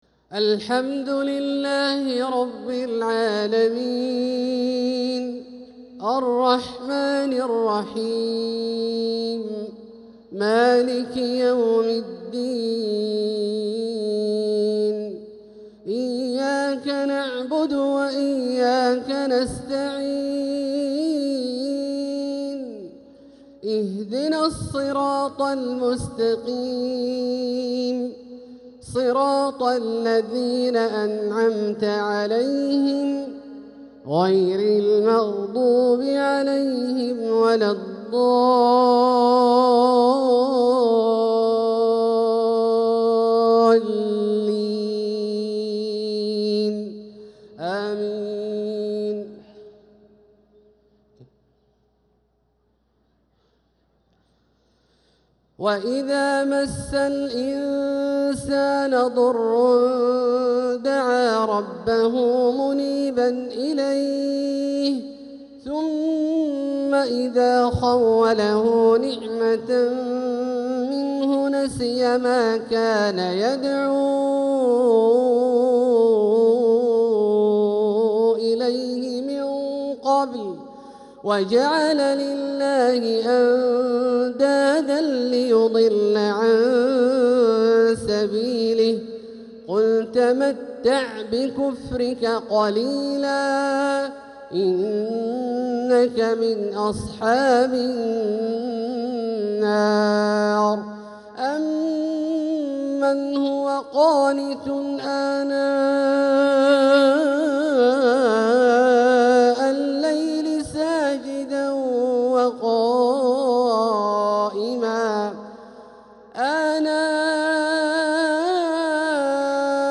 عشاء السبت 8-9-1446هـ من سورتي الزمر 8-9 و الذاريات 15-23 | Isha prayer from Surat az-Zumar & ad-Dhariyat 8-3-2025 > 1446 🕋 > الفروض - تلاوات الحرمين